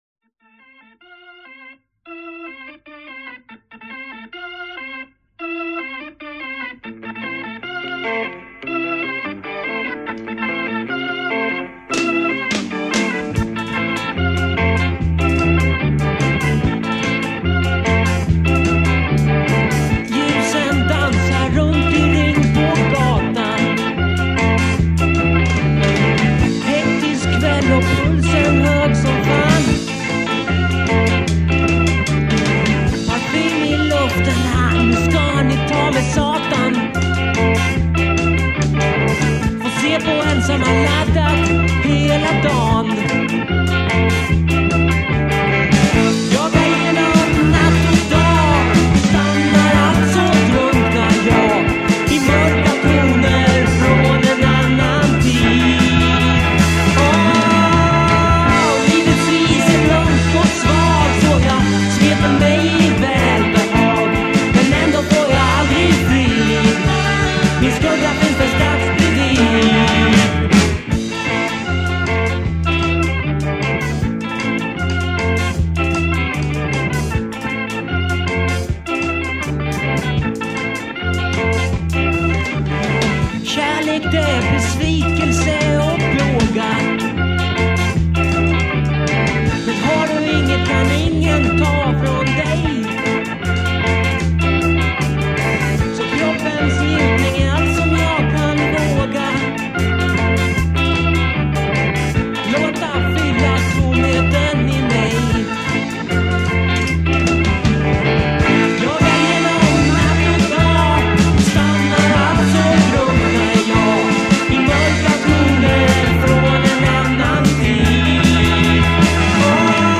Drums
Voice, Guitar
Organ, Flute
Bass